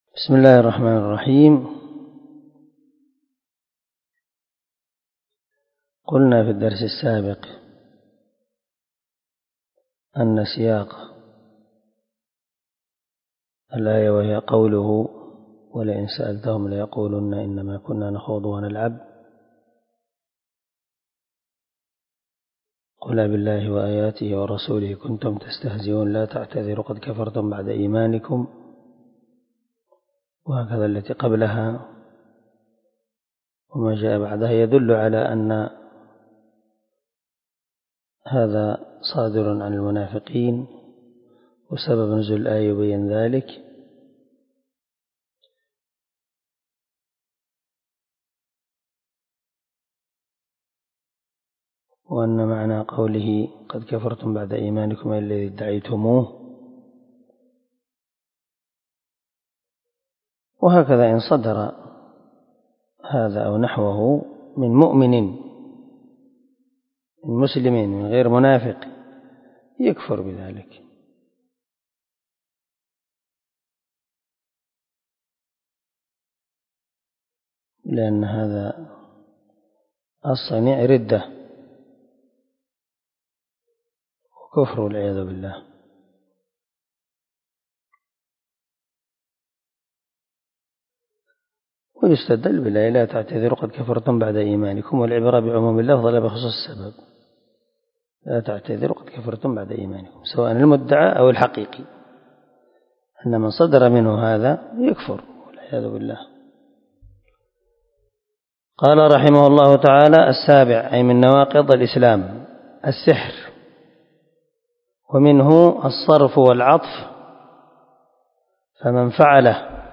🔊الدرس 17 الناقض السابع ( من شرح الواجبات المتحتمات)
الدرس-17-الناقض-السابع.mp3